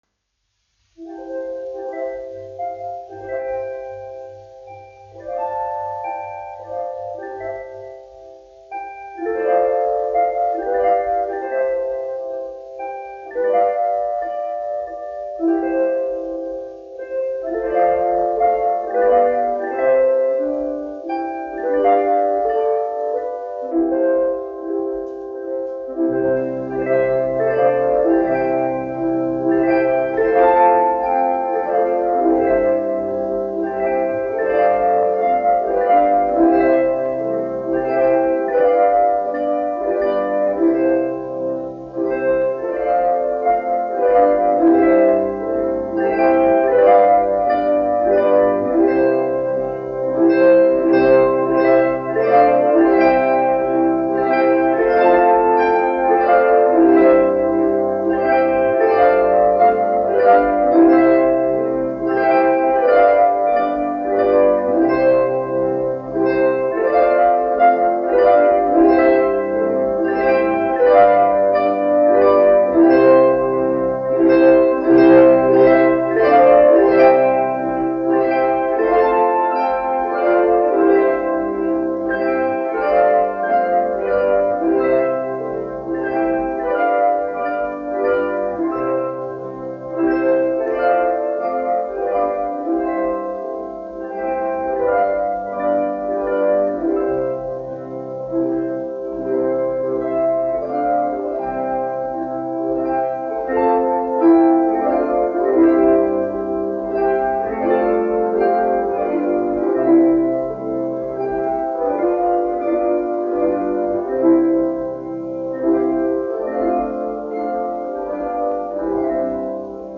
Gaismiņa ausa : latviešu tautas dziesma
1 skpl. : analogs, 78 apgr/min, mono ; 25 cm
Kokles mūzika